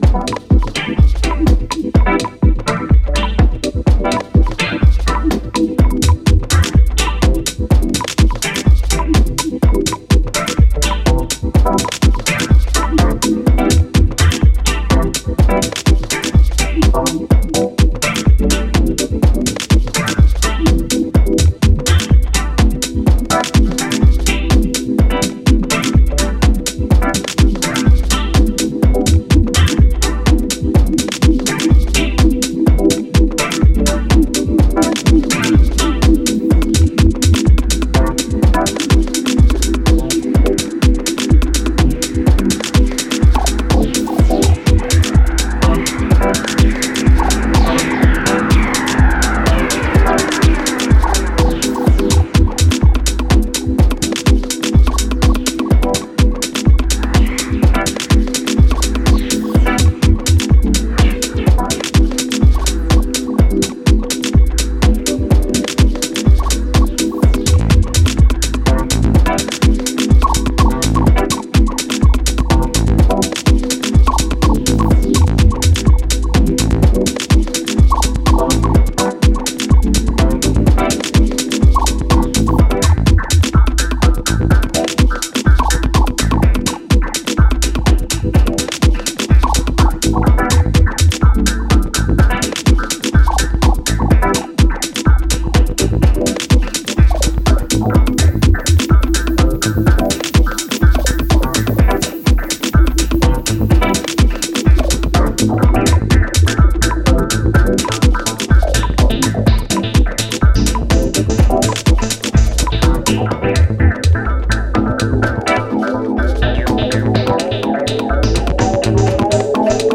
トリッピーで仄かにジャジーな要素を絡めたアトモスフェリックなディープ・ハウスです。